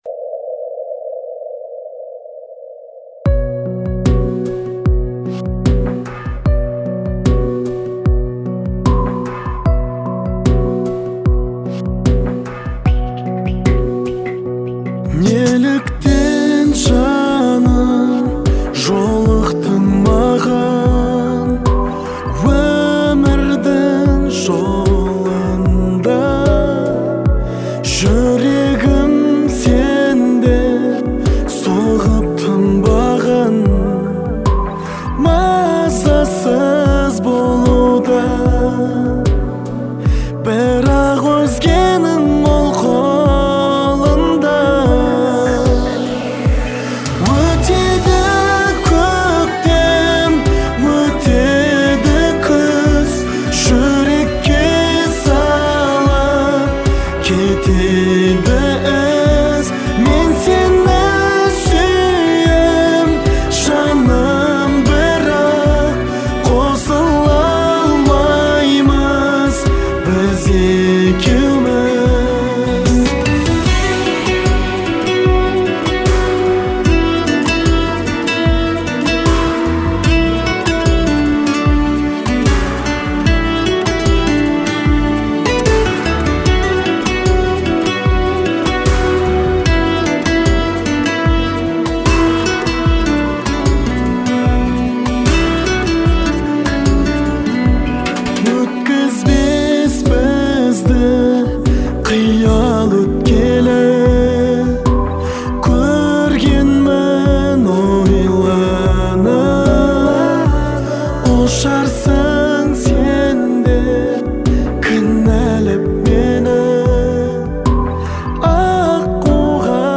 мощном вокале и чувственной интерпретации